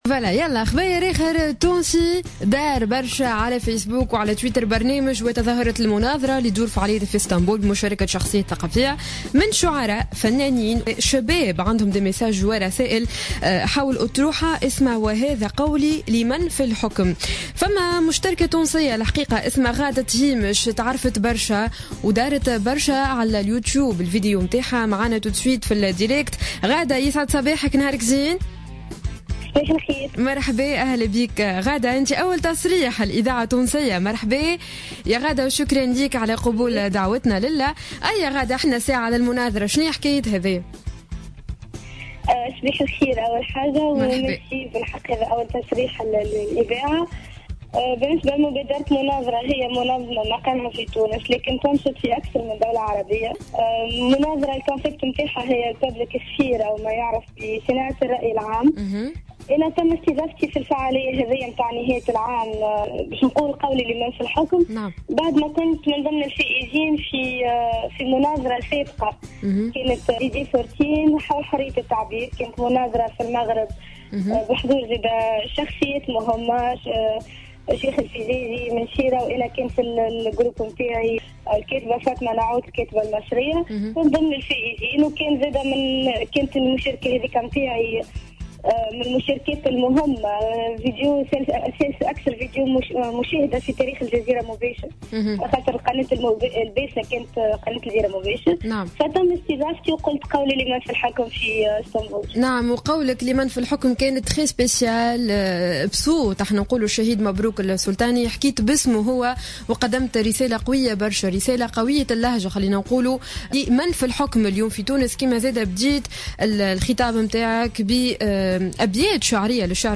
استضافت الجوهرة أف أم اليوم الاثنين 04 جانفي 2016 الفتاة التونسية